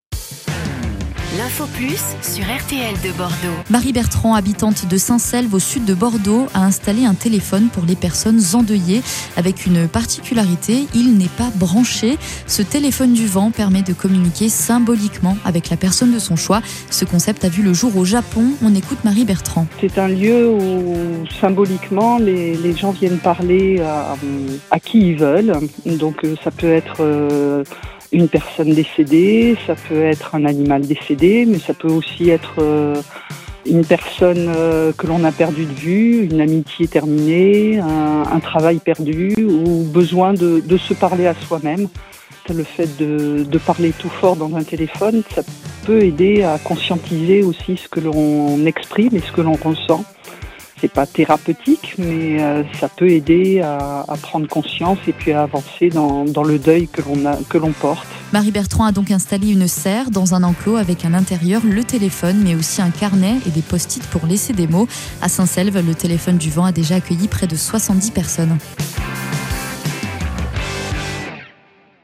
Plongez dans un épisode émouvant de RTL2 qui explore le concept du “téléphone du vent” : un espace symbolique pour dialoguer avec l’absence, libérer ses émotions et avancer dans le deuil. Ce podcast explique pourquoi ce lieu singulier peut devenir un véritable pont entre le passé et le présent — une façon douce et puissante d’adresser des mots que l’on n’ose parfois pas prononcer.